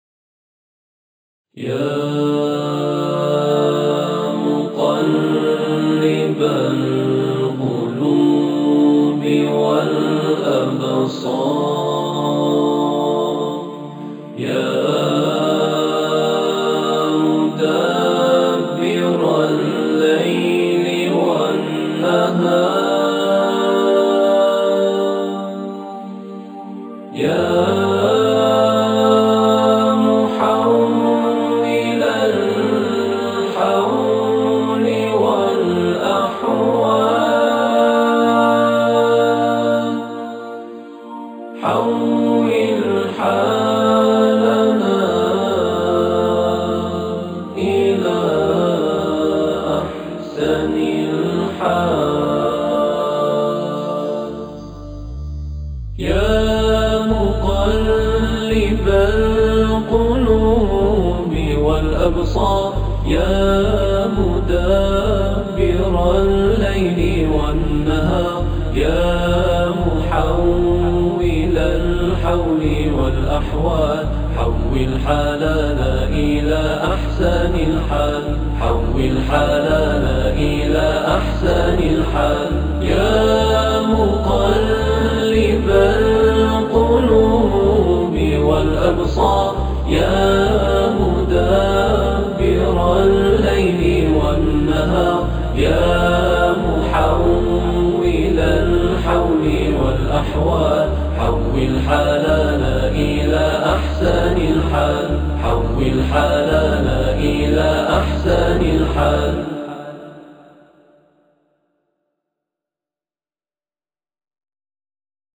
تواشیح
گروه تواشیح میعاد قم